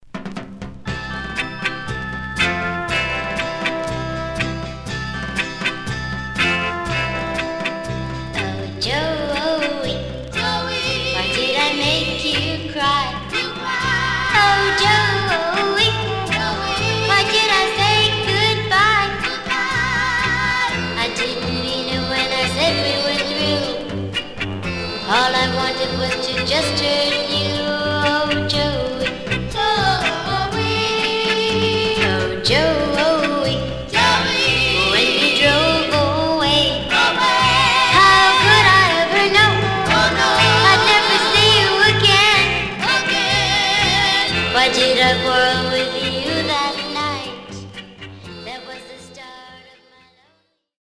Some surface noise/wear Stereo/mono Mono
White Teen Girl Groups